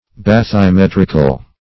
Bathymetric \Bath`y*met"ric\, Bathymetrical \Bath`y*met"ric*al\,